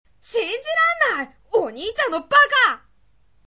このページはS03企画ボイスドラマ「僕とお兄ちゃん。」のページです。